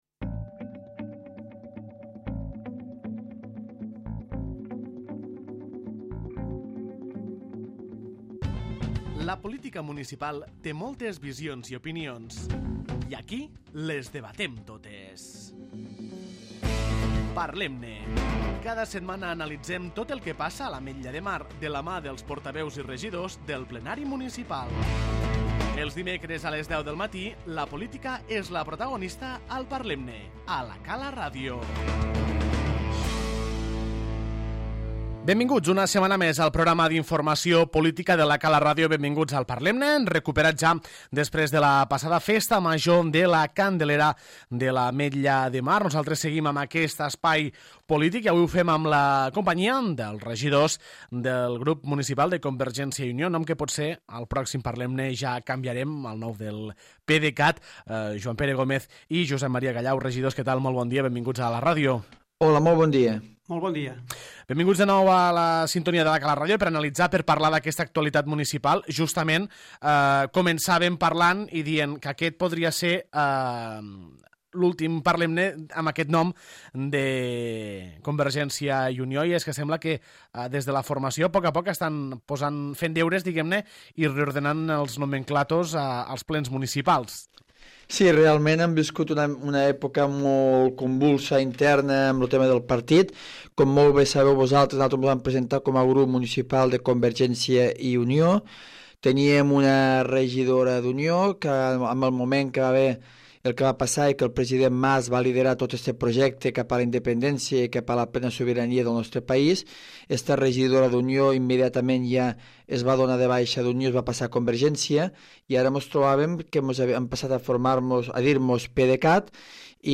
En l'espai polític de La Cala Ràdio ens han acompanyat els regidors del grup municipal de Convergència i Unió, Joan Pere Gómez i Josep Maria Callau, per parlar sobre temes econòmics, turístics, d'urbanitzacions i de pròximes mocions.